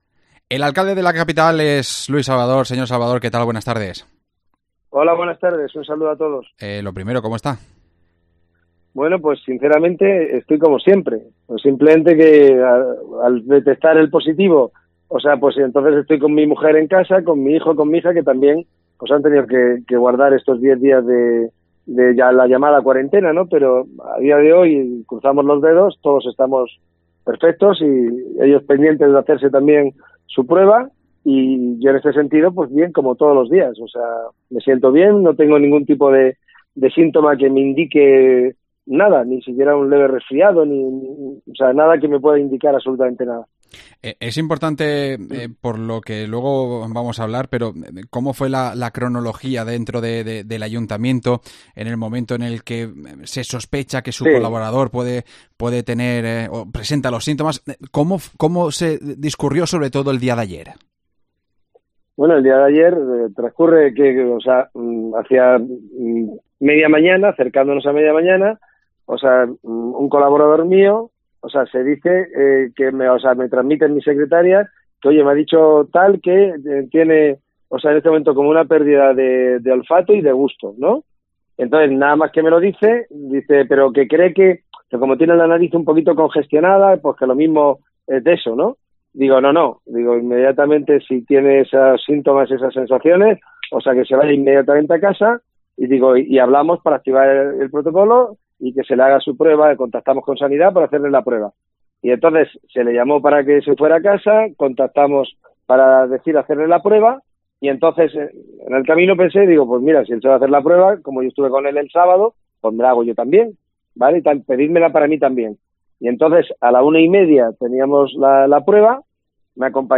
El alcalde de Granada ha pasado por COPE Granada pocas horas después de conocer su positivo por COVID 19